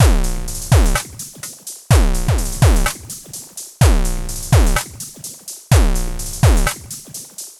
• drum sequence analog 1 - 126 - broken beat harsh.wav
Recorded internal, using a Volca Drum, modulated and a Komplete Audio 6 interface.
drum_sequence_analog_1_-_126_-_broken_beat_harsh_MwK.wav